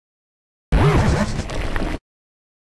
Archivo:Grito de Tangrowth.ogg
== Licencia == {{Archivo de audio}} Categoría:Gritos de Pokémon de la cuarta generación